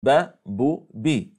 Likaså, när en person säger بُ بُ (BuBu)   tar det precis lika lång tid som att säga بو (Boo) .